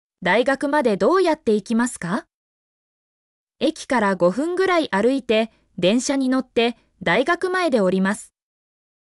mp3-output-ttsfreedotcom-57_zqqr76FX.mp3